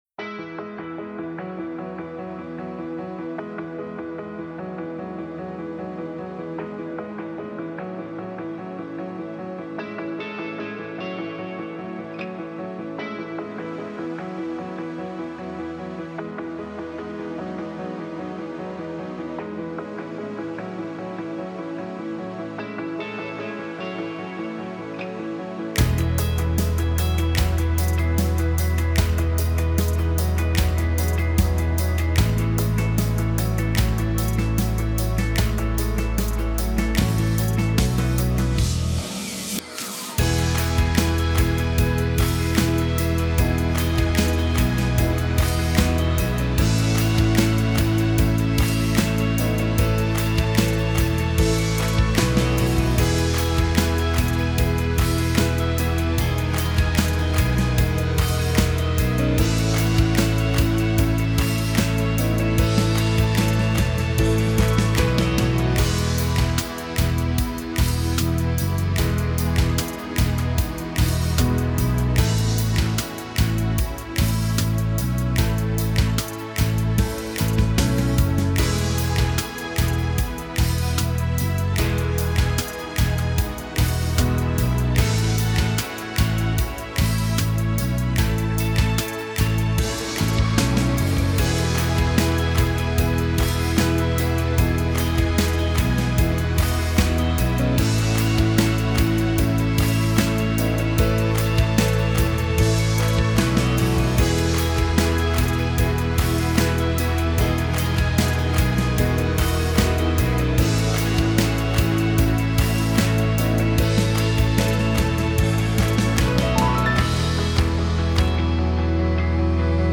Сведение акустического трека